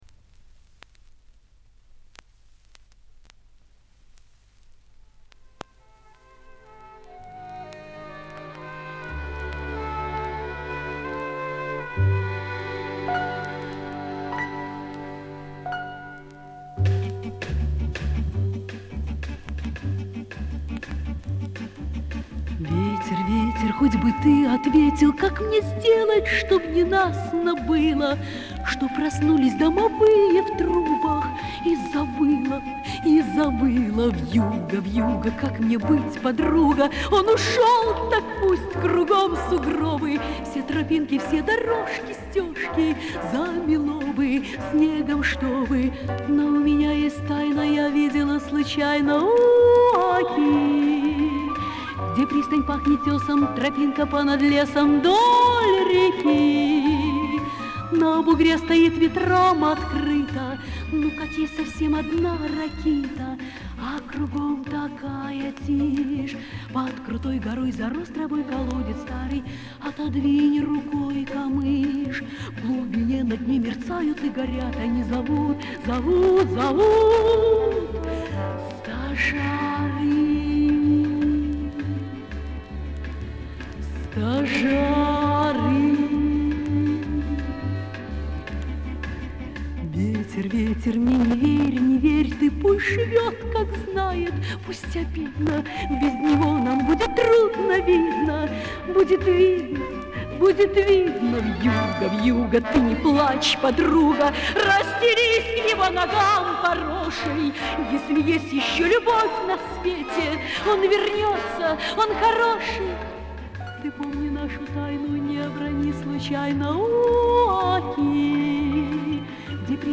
Инструментальный ансамбль